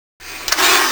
c_viper_atk1.wav